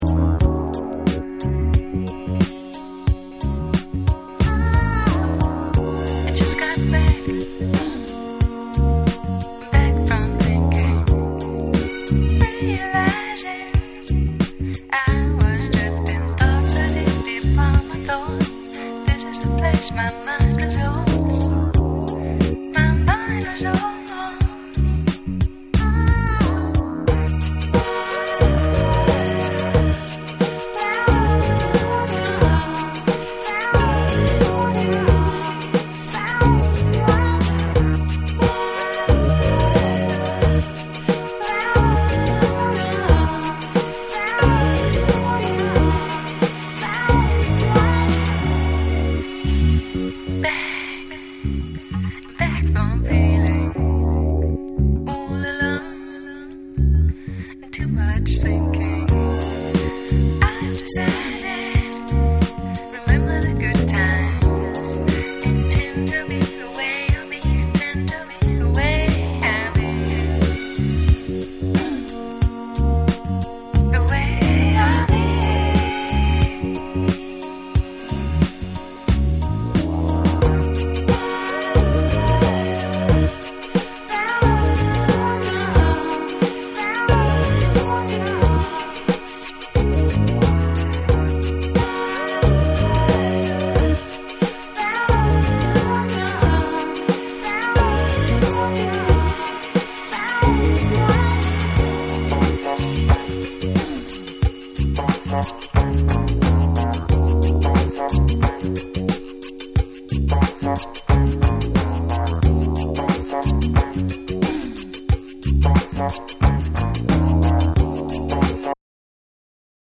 TECHNO & HOUSE / CHILL OUT